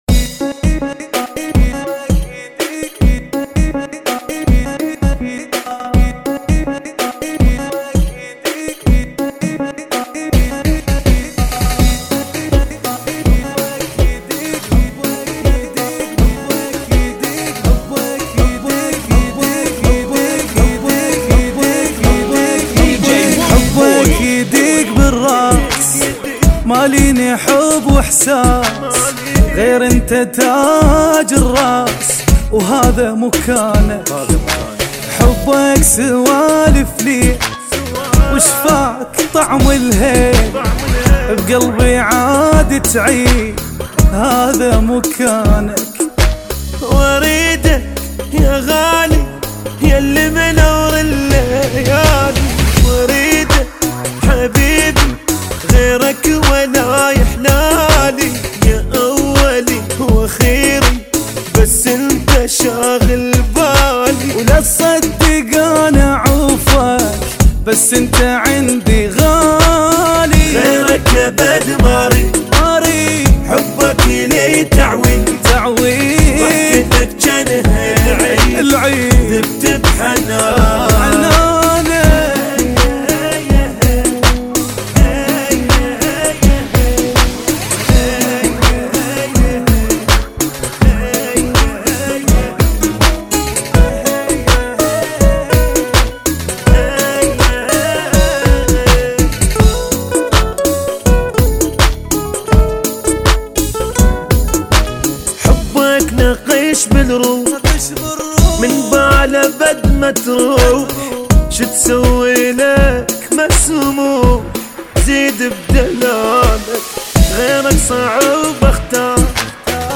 [ 82 Bpm ]